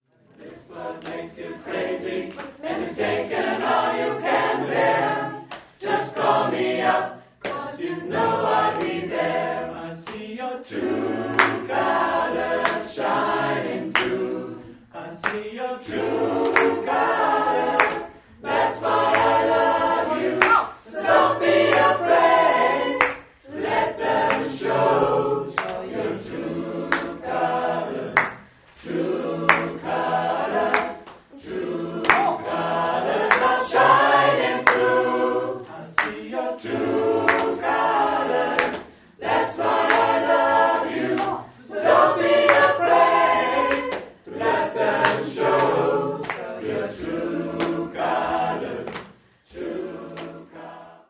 Chorversion